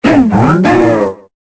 Cri de Miasmax dans Pokémon Épée et Bouclier.